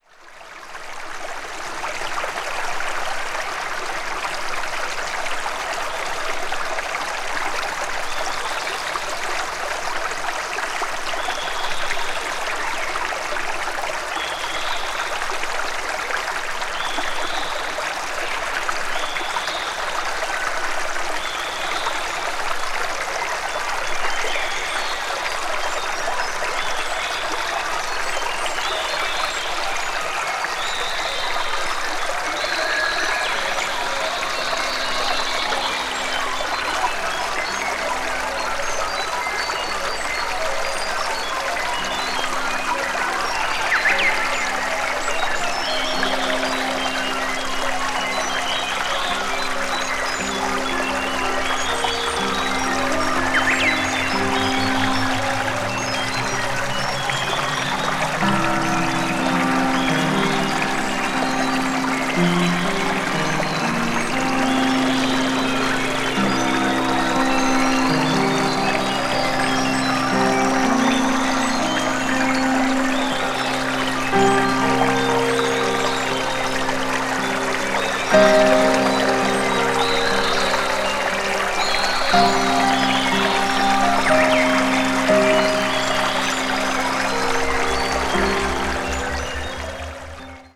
media : EX/EX(わずかにチリノイズが入る箇所あり)
ambient   electronic   meditation   new age   synthesizer